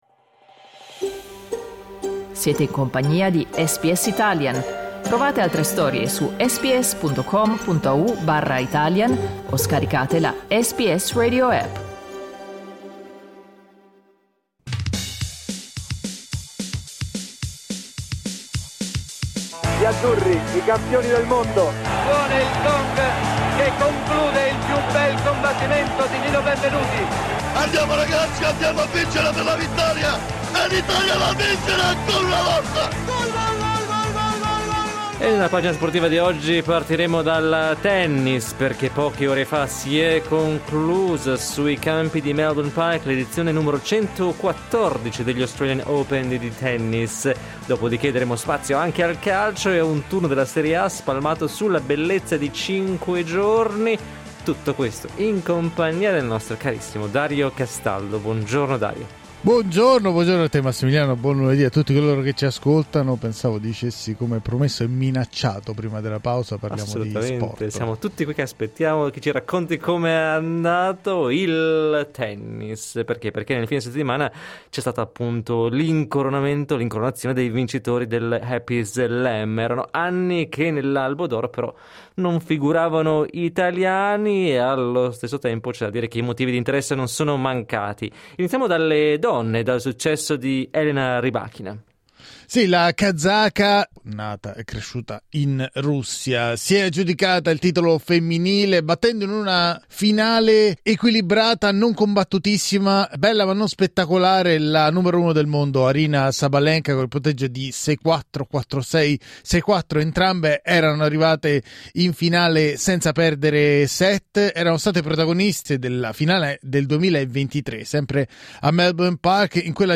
Ascolta le parole dei protagonisti del fine settimana sportivo.